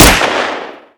pistol_fire2.wav